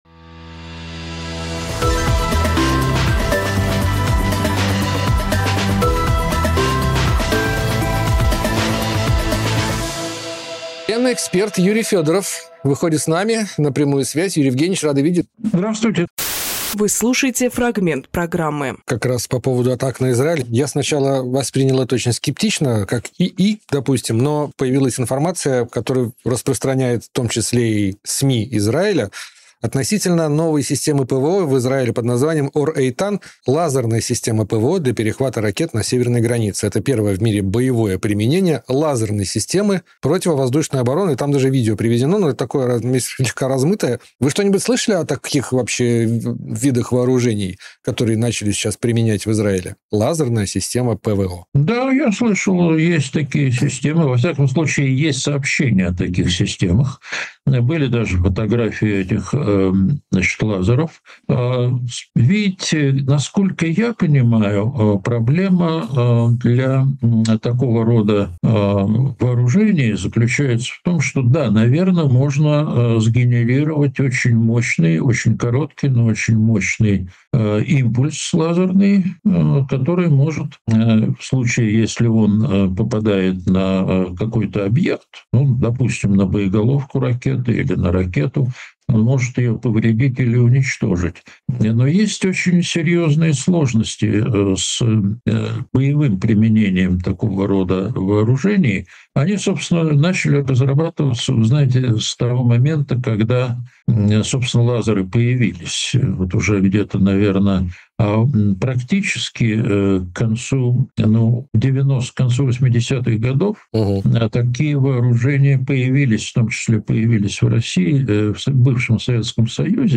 Фрагмент эфира от 02.03.26